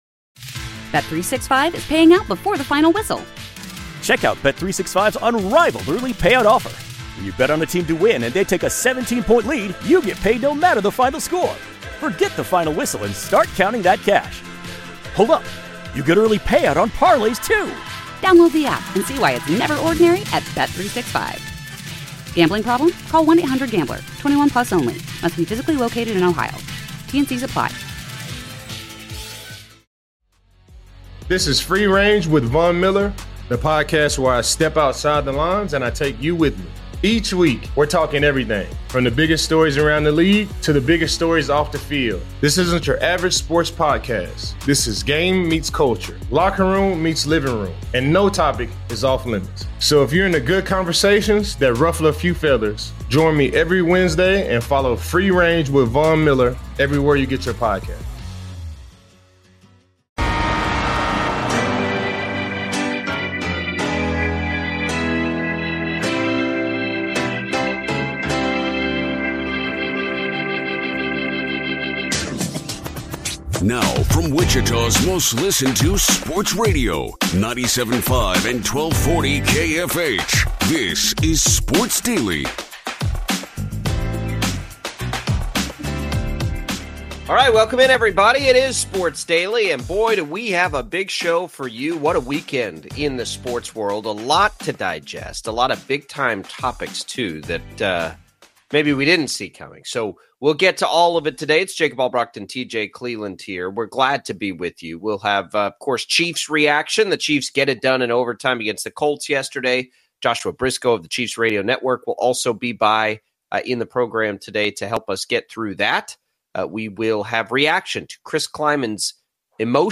Wichita's popular morning local sports talk radio show is Sports Daily